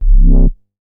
MoogFilta 004.WAV